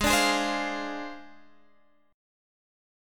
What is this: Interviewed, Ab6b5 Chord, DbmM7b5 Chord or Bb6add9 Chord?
Ab6b5 Chord